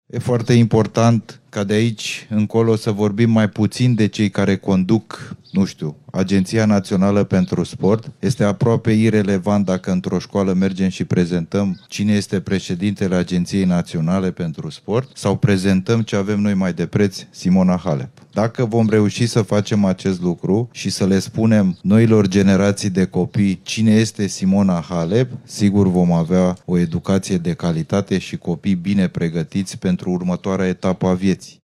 Centrul Național de Tenis din Capitală poartă de acum numele Simonei Halep: fost număr 1 în tenisul mondial și câștigătoare Roland Garros și Wimbledon. Sportivii ar trebui să fie în prim plan în școli, nu șefii agențiilor naționale pentru sport, a declarat la evenimentul inaugural președintele Agenției Naționale pentru Sport, Constantin Bogdan Matei.